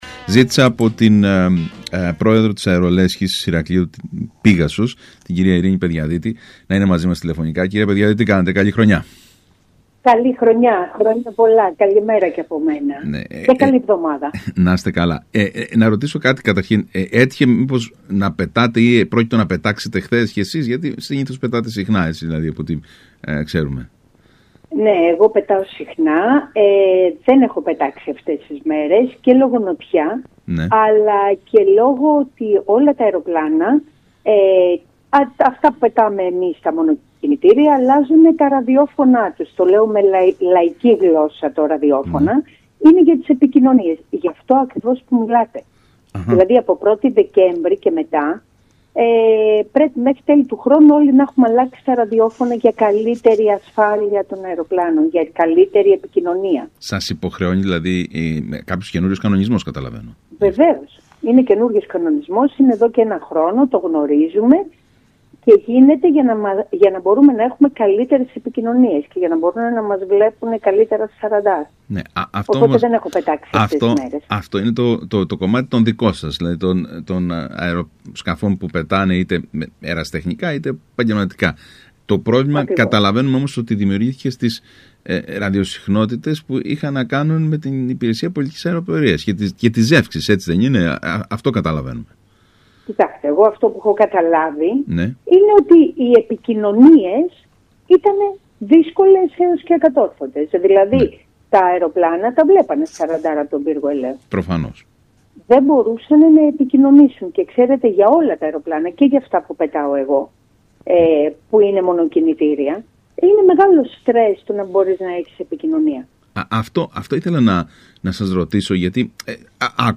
μιλώντας στον ΣΚΑΙ Κρήτης